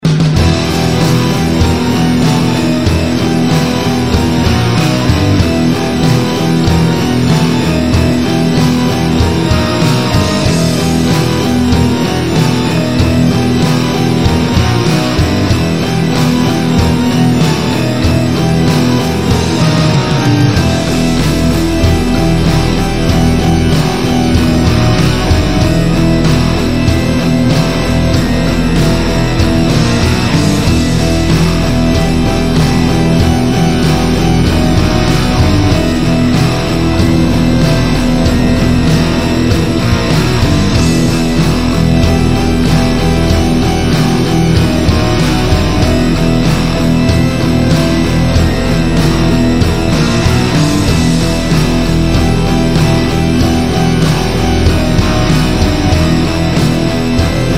• Качество: 128, Stereo
громкие
без слов
электрогитара
doom metal
Необычный doom metal